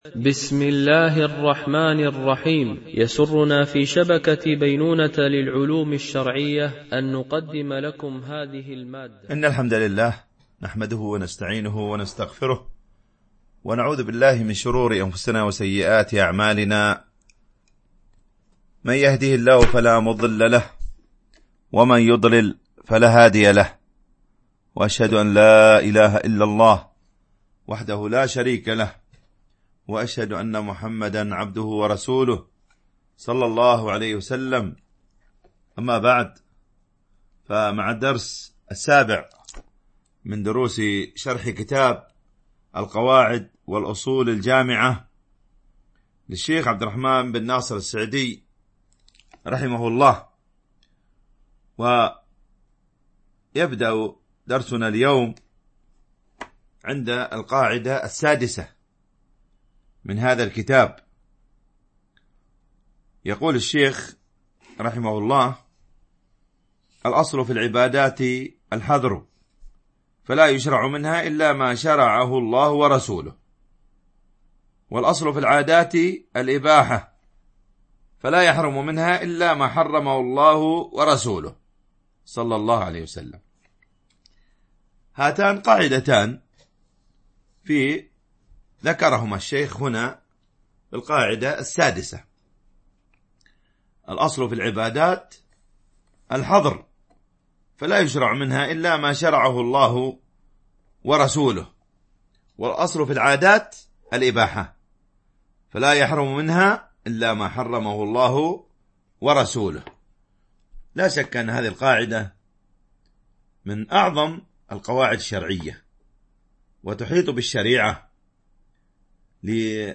الدرس 7
التنسيق: MP3 Mono 22kHz 32Kbps (CBR)